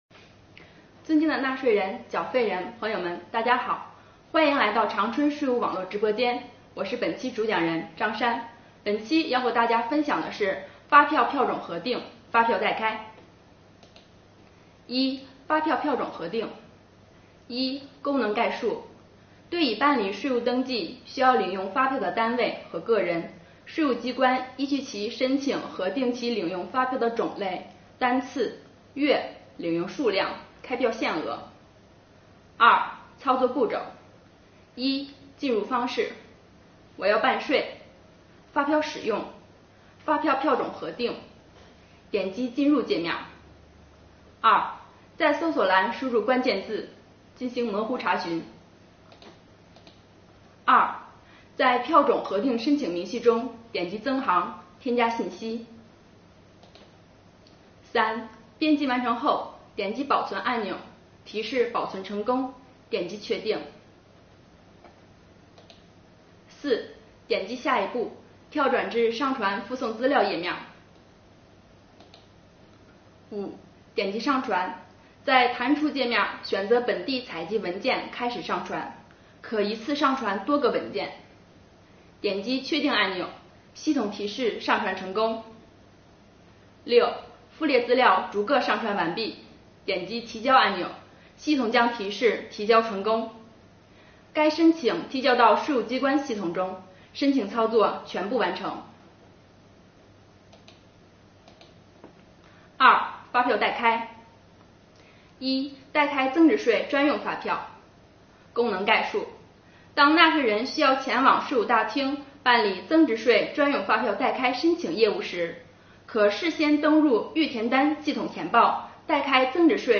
2020年第35期直播回放:非接触办税指引系列之五：电子税务局发票票种核定及发票代开相关内容介绍。